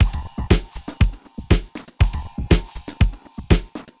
Code Red (Drumsl) 120BPM.wav